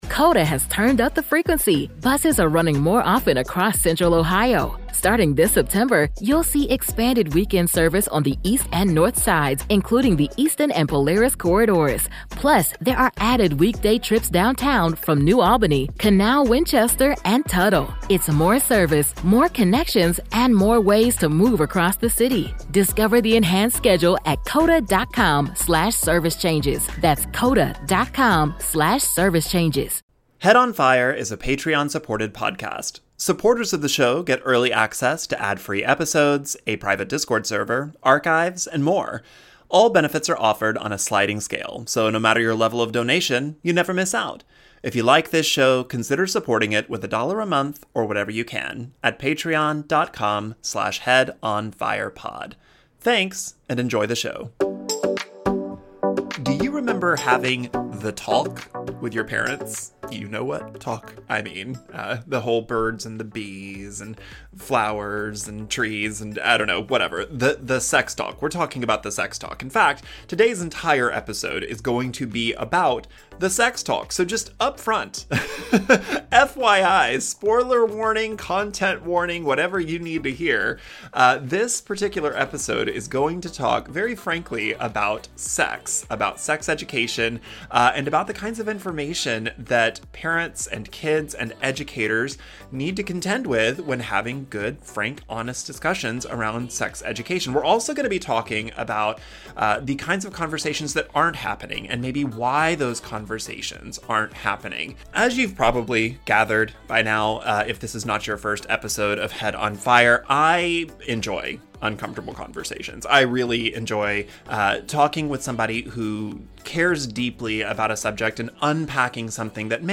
We have a deep dive discussion that will, hopefully, help you get comfortable with this uncomfortable topic. Not that I think it needs to be said, but this is a frank discussion of sex education. Correct names for body parts, discussions of sex, and other related topics will come up.